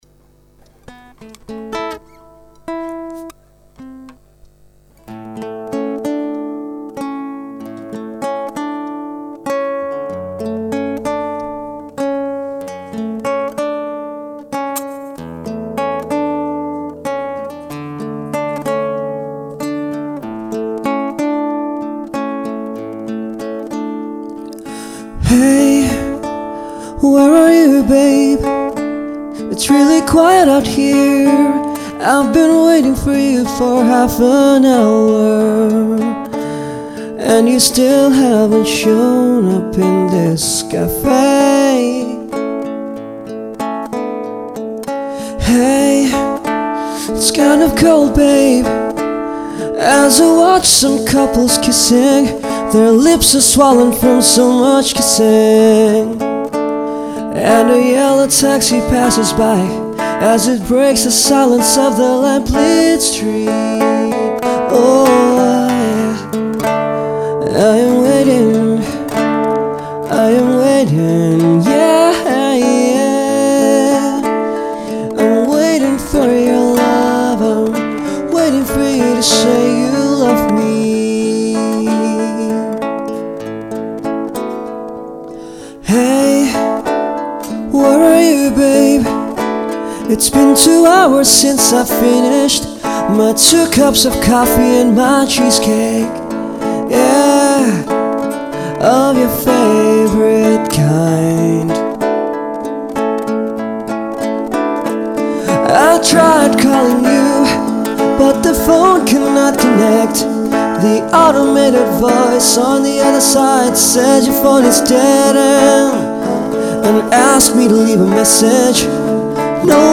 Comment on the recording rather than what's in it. recorded at Dubai Marina